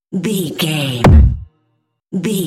Cinematic stab hit trailer
Sound Effects
Atonal
heavy
intense
dark
aggressive